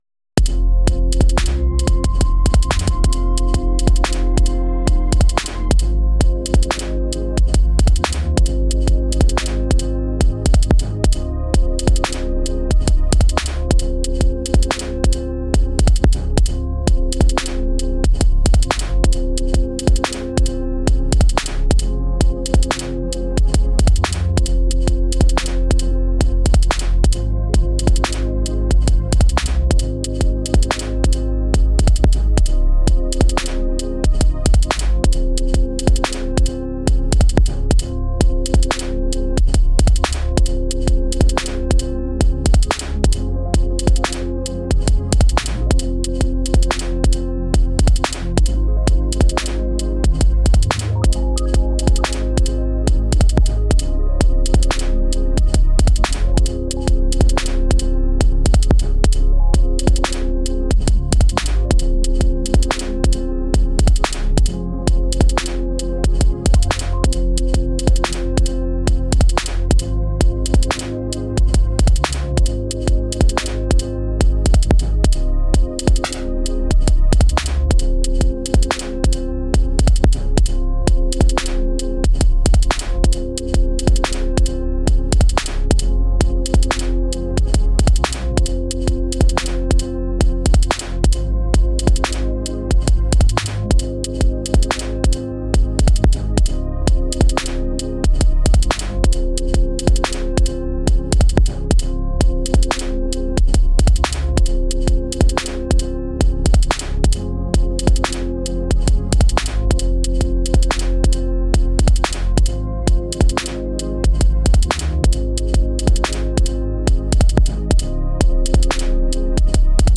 I can’t get over the freaking swing on this thing!
You can’t really hear the shuffle here due to my P-FX sequencing but still, this pattern is very much straight on beat when it’s not in full swing.